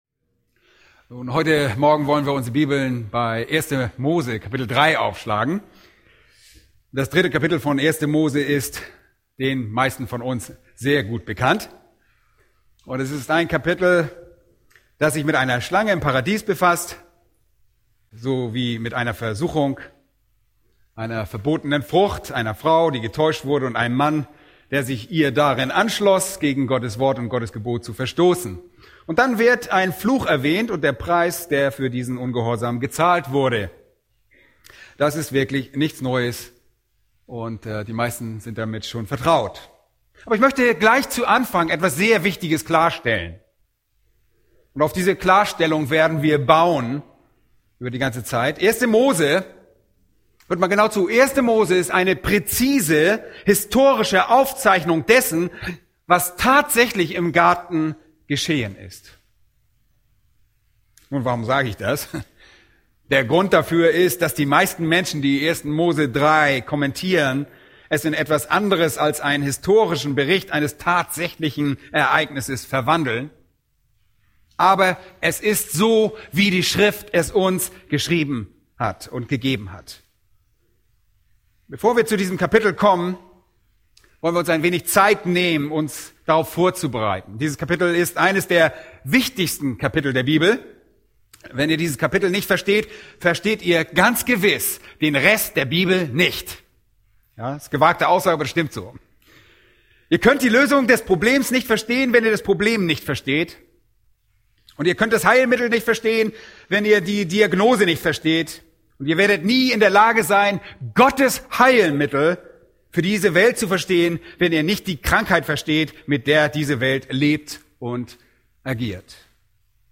Bibelstunden - Bibelgemeinde Barnim